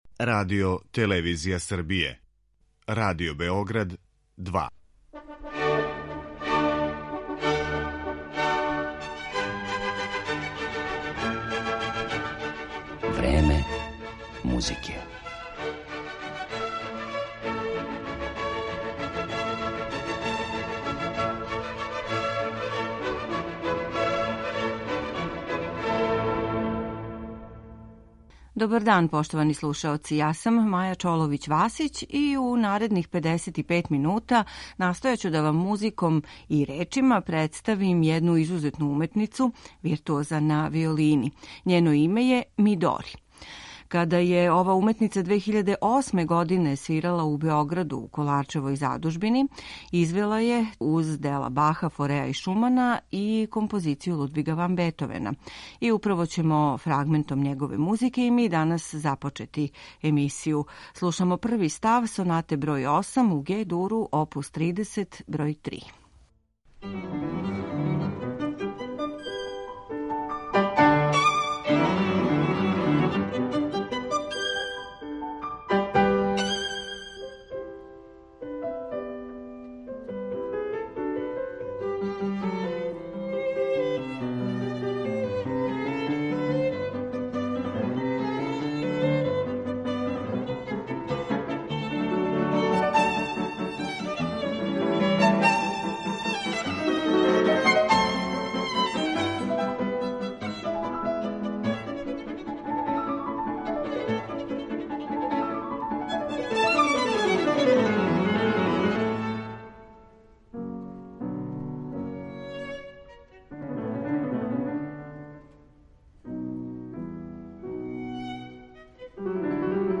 Виолинисткиња Мидори је врхунски извођач, наступа у најпрестижнијим светским дворанама као солиста и камерни музичар.
Портрет ове уметнице насликаћемо у данашњој емисији музиком Бетовена, Баха, Паганинија, Дворжака, Равела и Шостаковича.